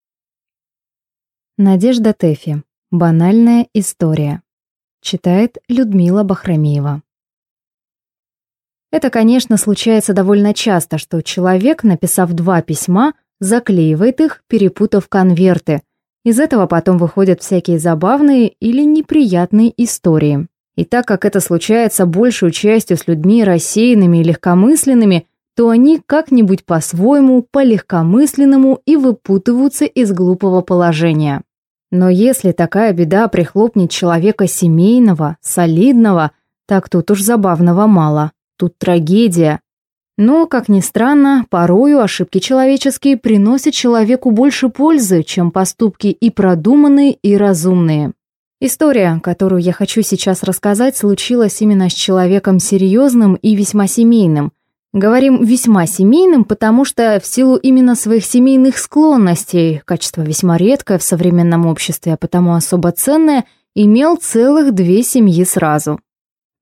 Аудиокнига Банальная история | Библиотека аудиокниг